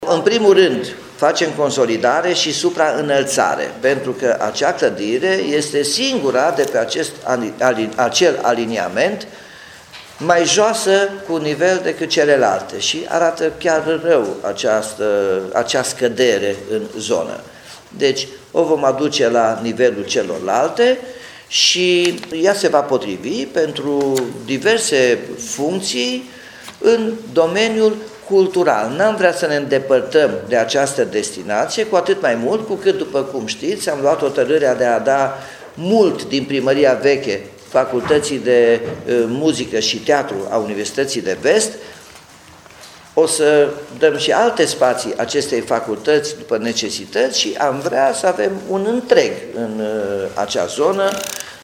Primarul Nicolae Robu a anunţat că firma a preluat amplasamentul, în cel mult două săptămâni urmând să fie semnat ordinul de începere a lucrărilor: